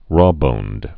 (rôbōnd)